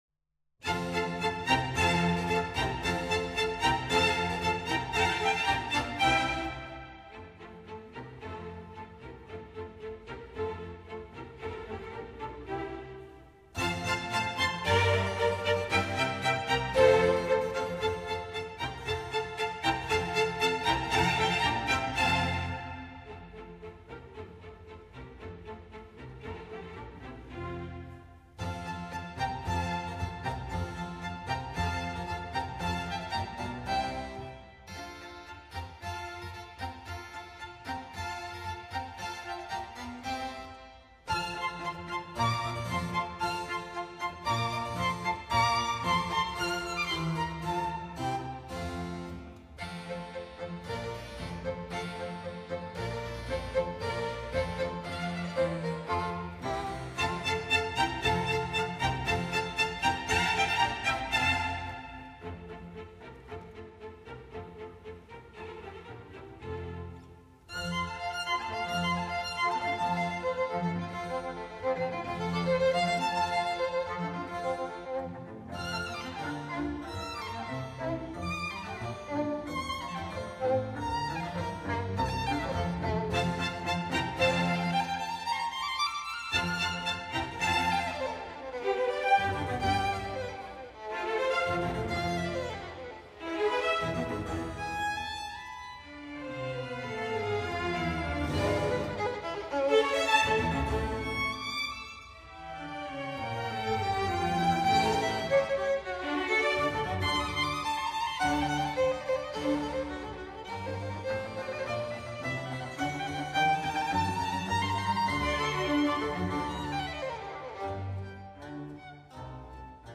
F大调，作品第8号，第三首
I. Allegro
第一乐章：农民载歌载舞，快板